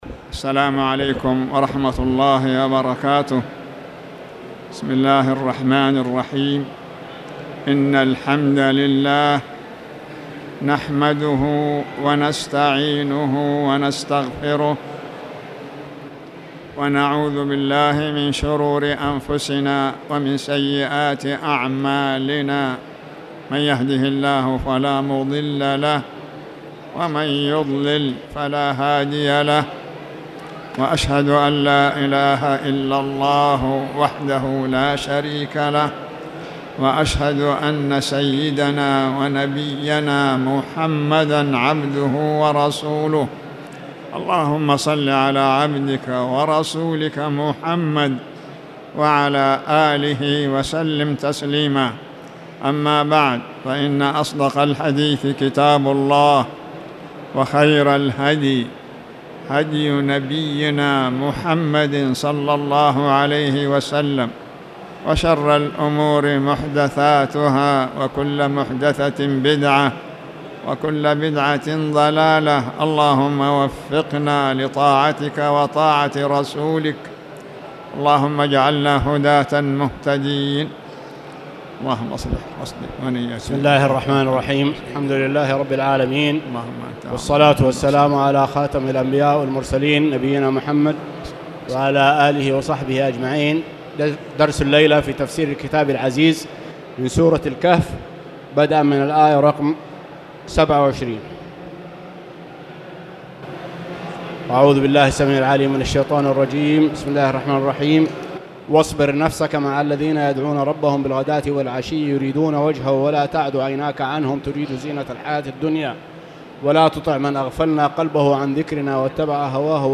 تاريخ النشر ٢٦ ذو الحجة ١٤٣٨ هـ المكان: المسجد الحرام الشيخ